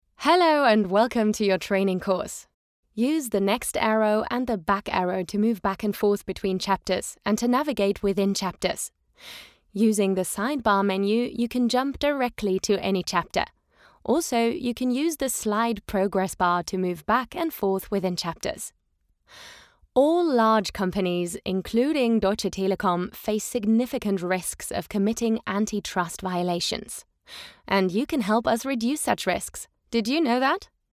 E-learning
My voice is relatable, contemporary and youthful with a warm and textured sound.